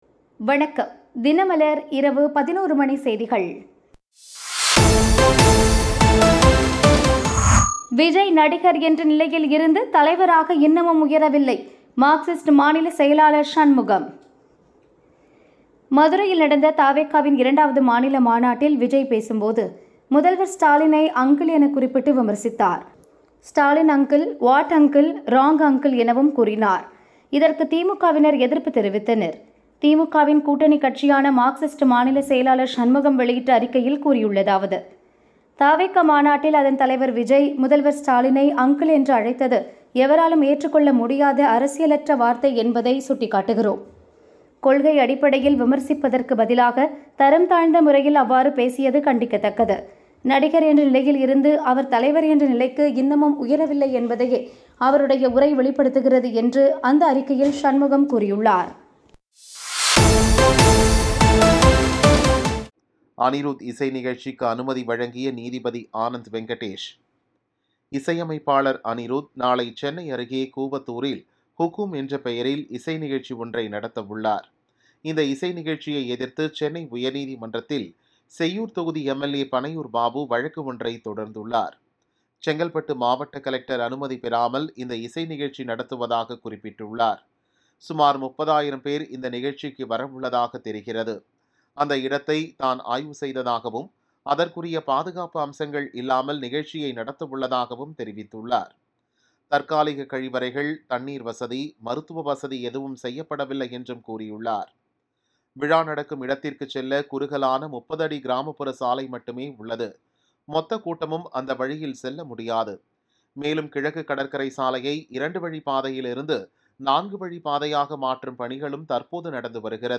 தினமலர் இரவு 11 மணி செய்திகள் 22 AUG 2025